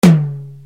SP TOM2.wav